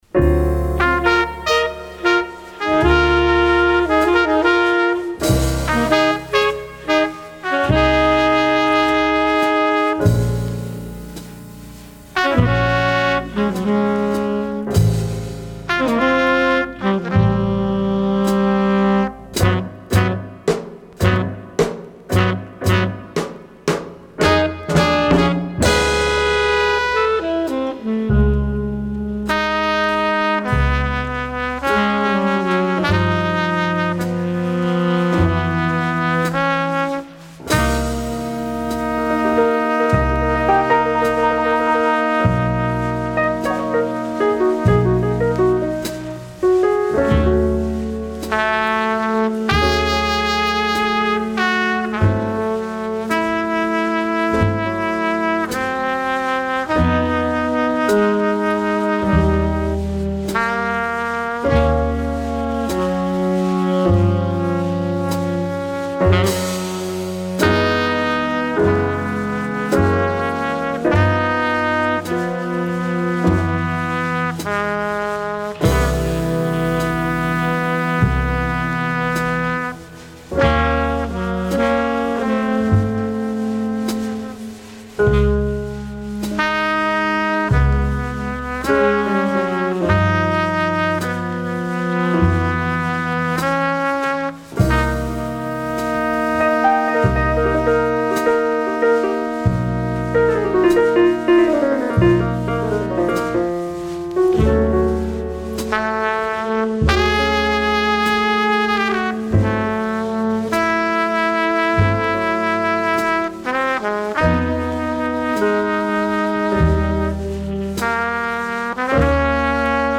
• Original 1959 mono pressing
trumpet
tenor saxophone
piano
bass
drums
is a beautiful melancholy number